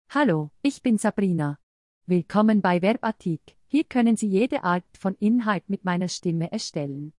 FemaleSwiss Standard German
Sabrina is a female AI voice for Swiss Standard German.
Voice sample
Female
Swiss Standard German
Sabrina delivers clear pronunciation with authentic Swiss Standard German intonation, making your content sound professionally produced.